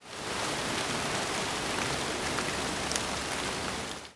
rain5.ogg